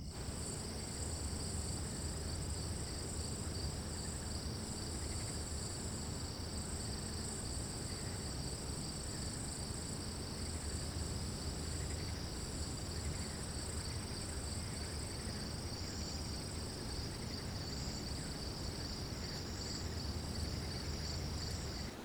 室外院子1.wav